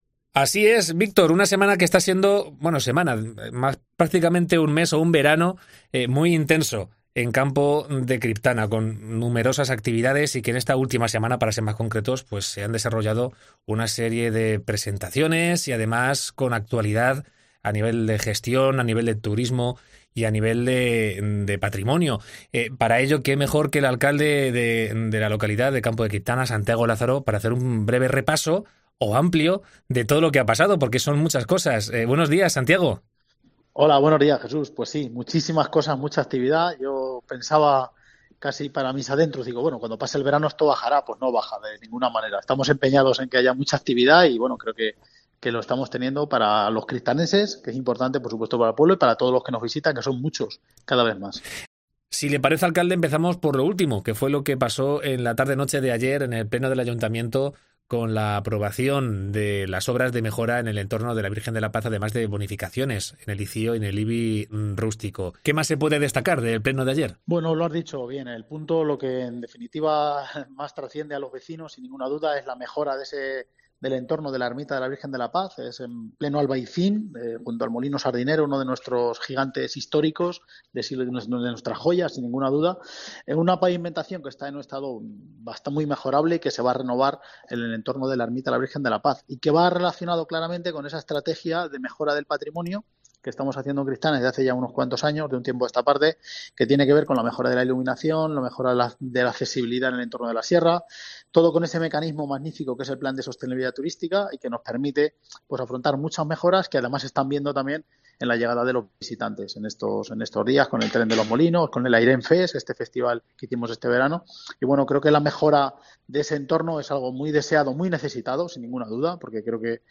Entrevista a Santiago Lázaro, alcalde de Campo de Critpana - 30 de septiembre de 2022